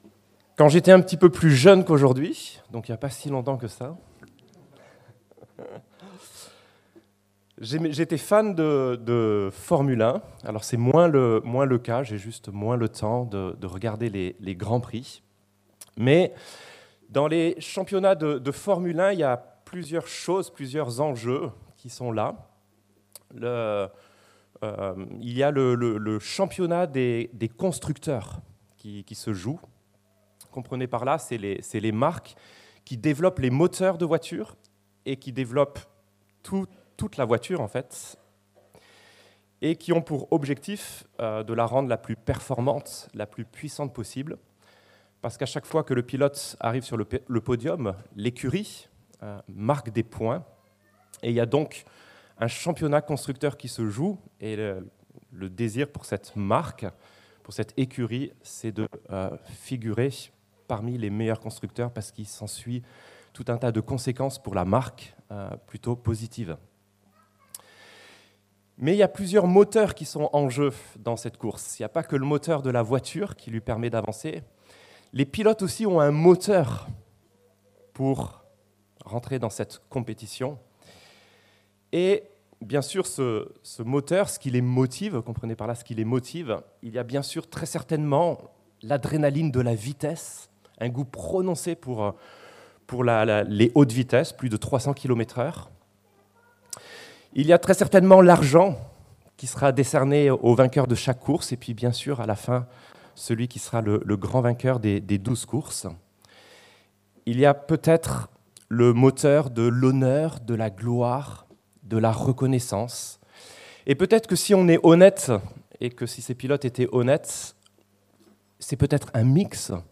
La grâce de Dieu qui transforme - Prédication de l'Eglise Protestante Evangélique de Crest sur le livre de l'Exode